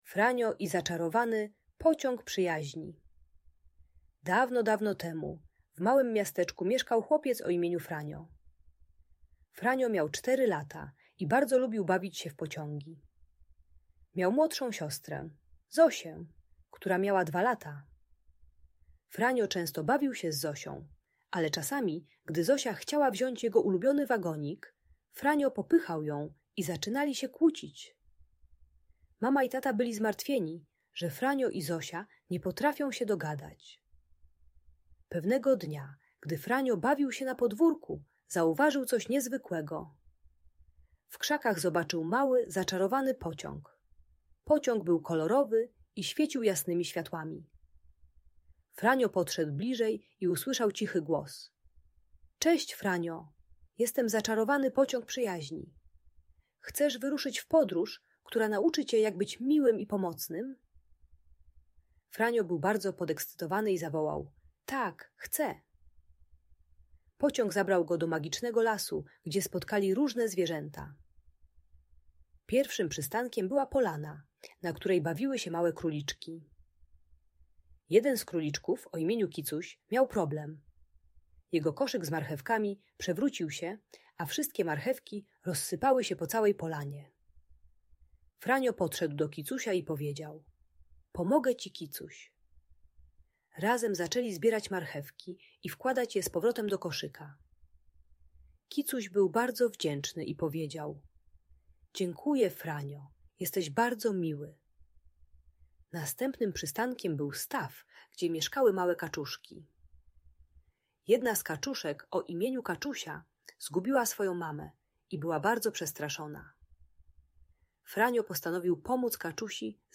Zaczarowany Pociąg Przyjaźni - Bunt i wybuchy złości | Audiobajka